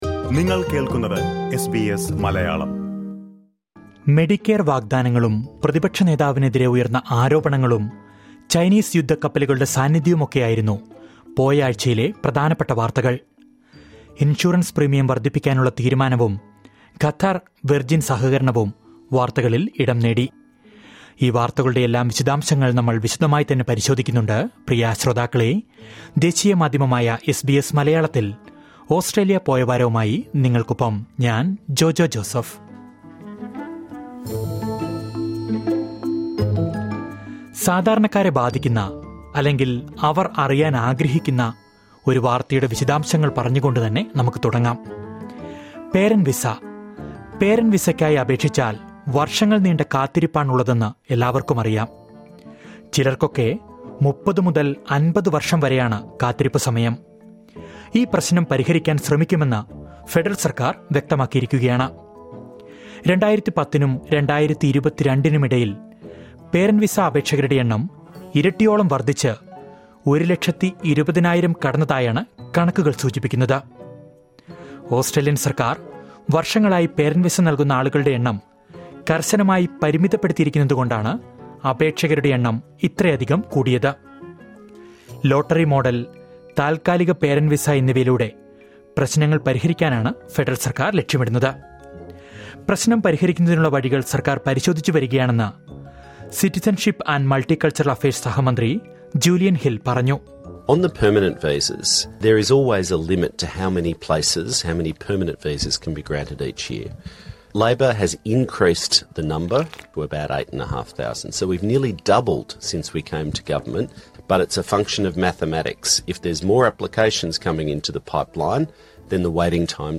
ഓസ്‌ട്രേലിയയിലെ കഴിഞ്ഞ ഒരാഴ്ചയിലെ പ്രധാന വാർത്തകൾ ചുരുക്കത്തിൽ കേൾക്കാം...